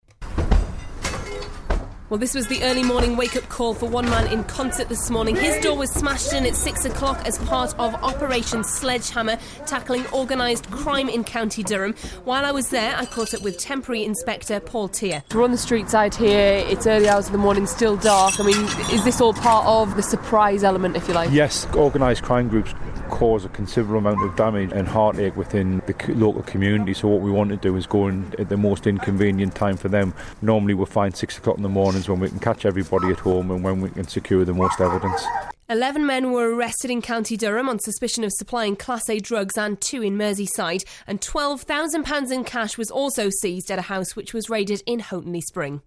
Out in Consett on 30/01/13 with Durham Police at 6am.